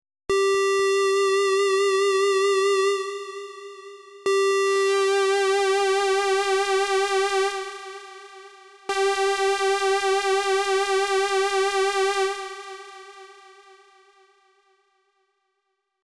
Тут резонанс на максимум а частота среза где-то наполовину Следующие 2 - пресловутая стерильность во всей своей красе а - TAL, b - Железка И последние 2 - тест хоруса.